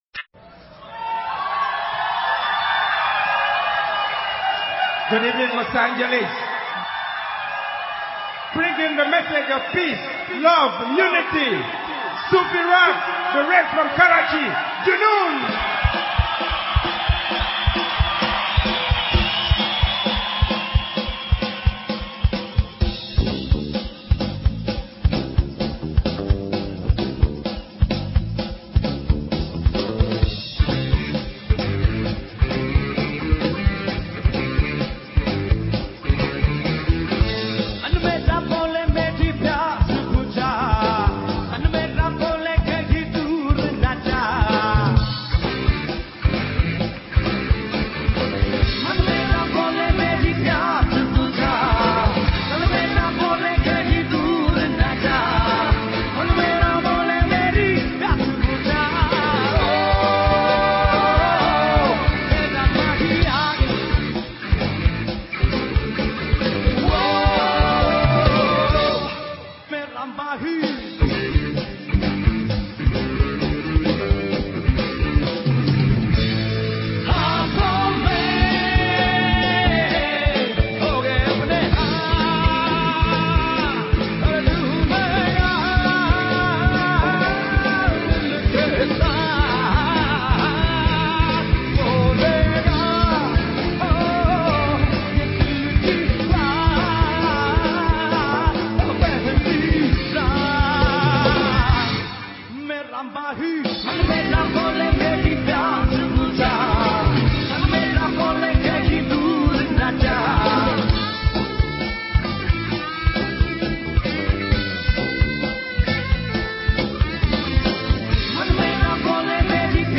Pakistani Songs